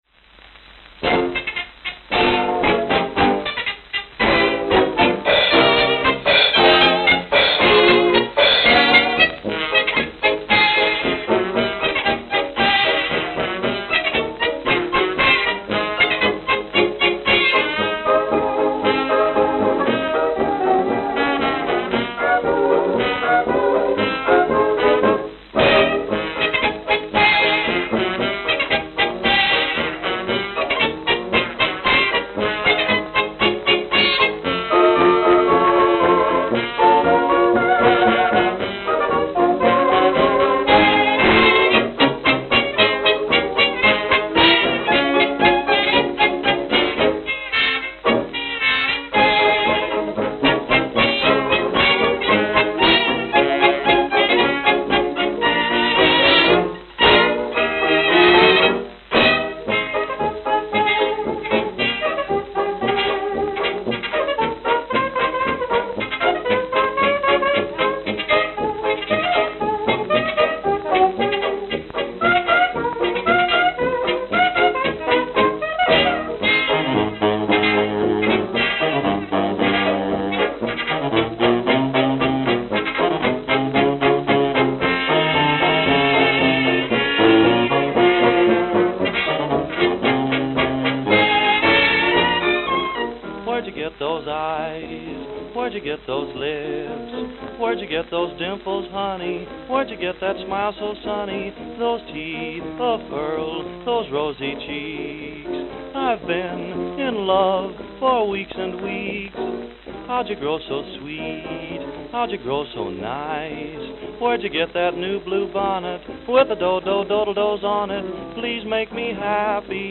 Note: Worn.